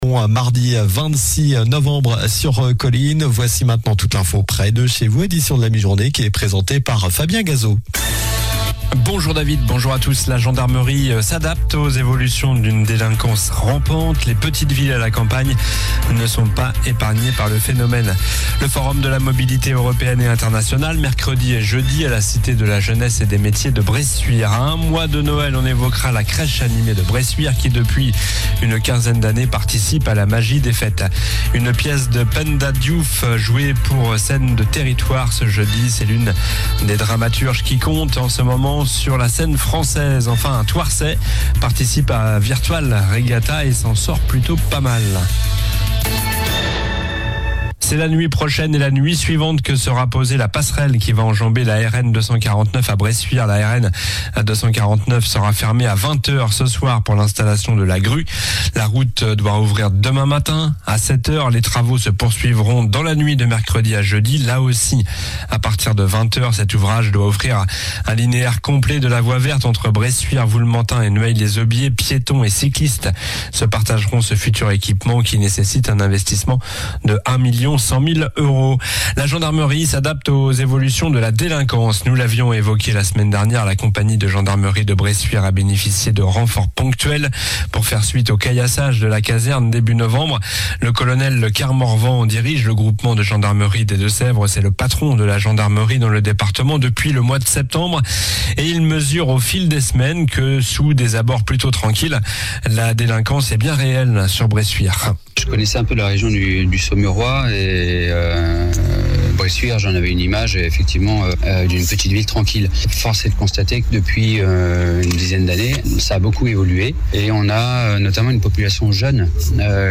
Journal du mardi 26 novembre (midi)